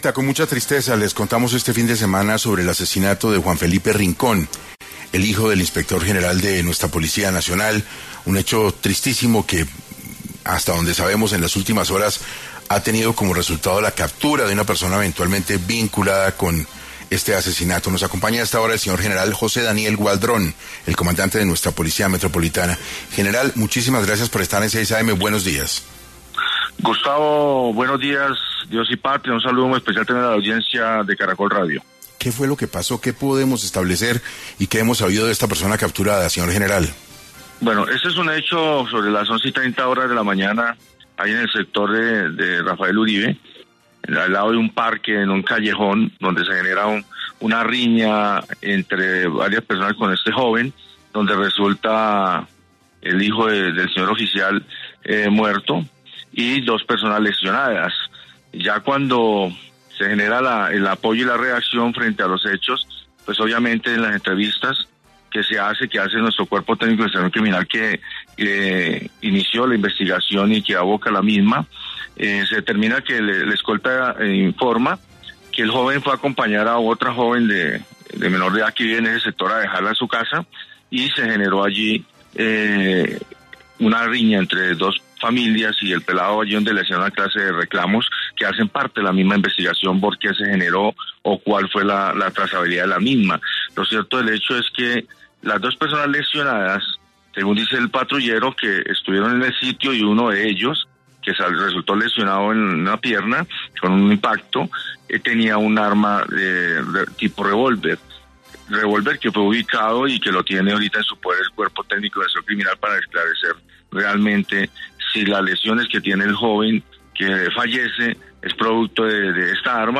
En Caracol Radio estuvo el general José Daniel Gualdrón, comandante de la Policía Metropolitana de Bogotá